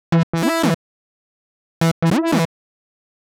Index of /musicradar/uk-garage-samples/142bpm Lines n Loops/Synths